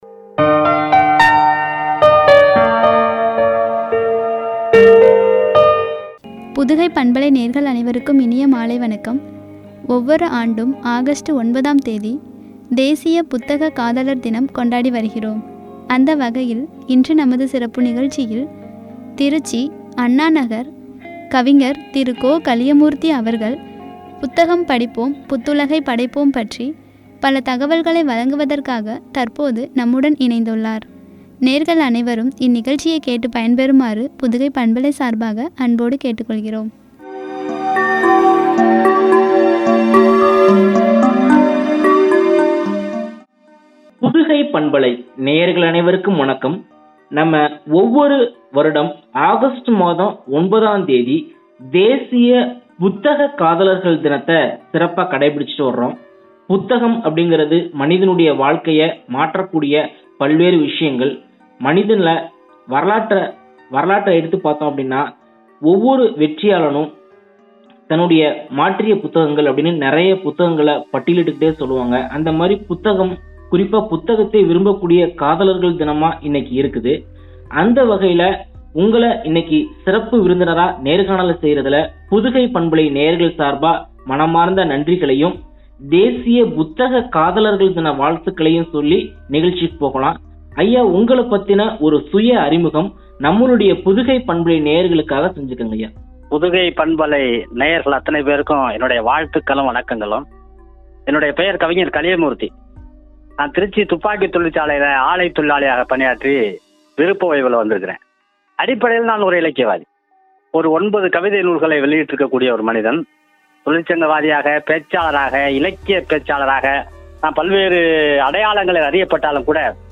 எனும் தலைப்பில் வழங்கிய உரையாடல்.